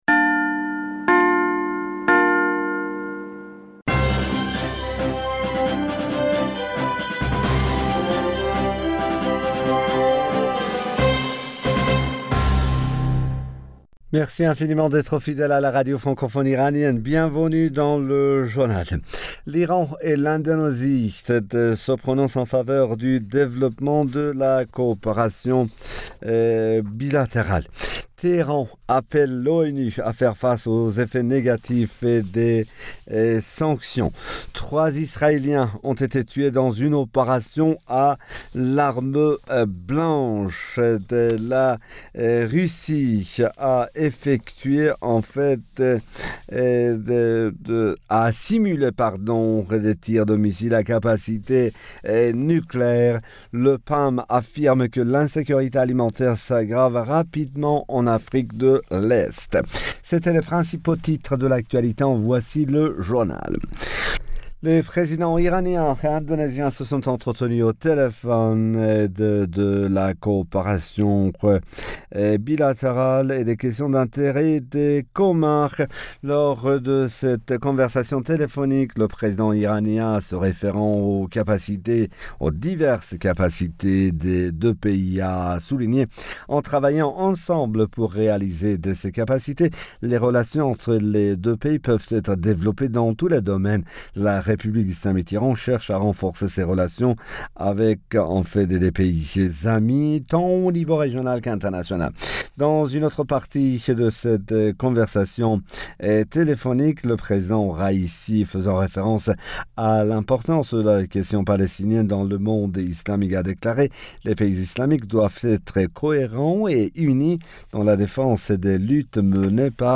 Bulletin d'information Du 06 Mai 2022